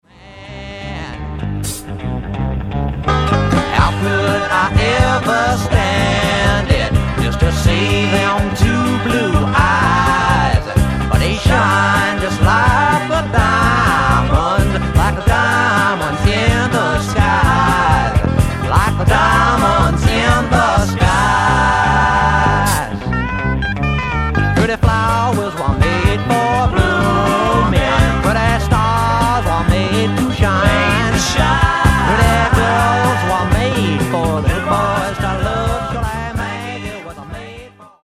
60年代からＮＹ/東海岸を拠点にそれぞれ活動していた、2人のシンガーと3人のインストゥルメンタリストからなる5人組。
バンジョー、フィドル、スティール・ギター